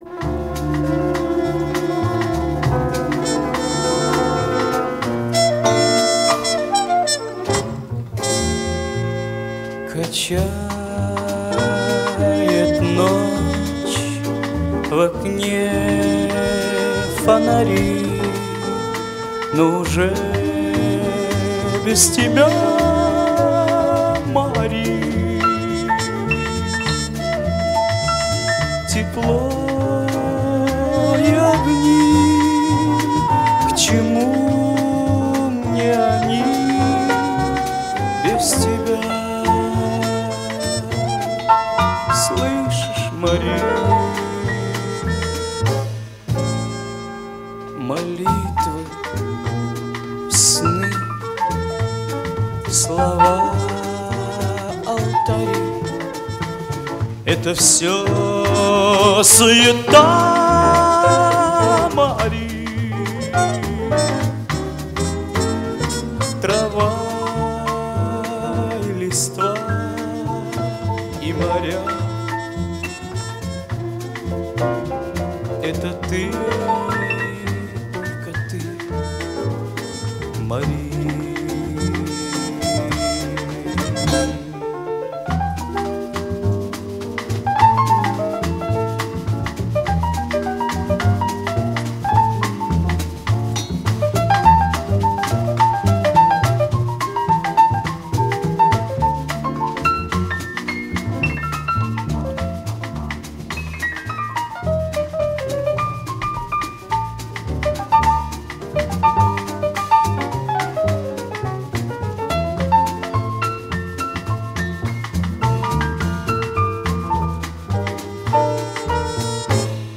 Не похоже на запись с концерта. Явно студийная работа.